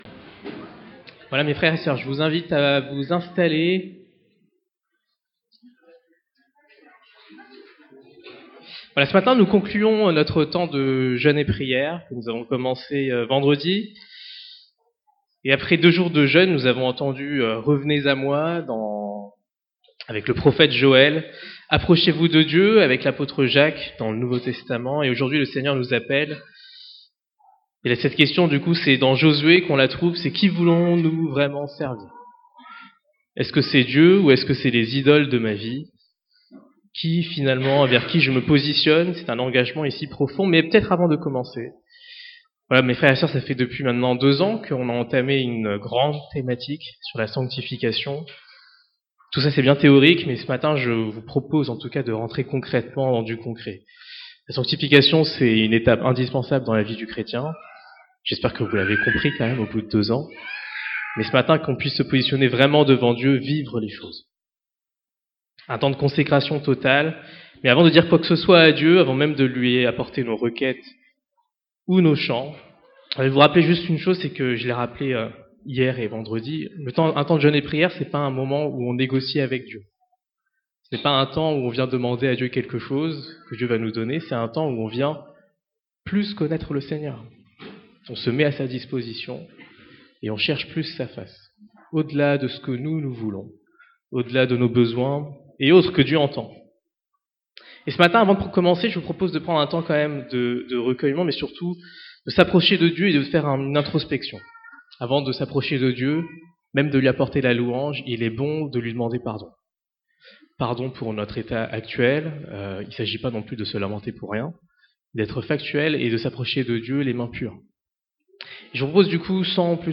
Culte spéciale de louange et prière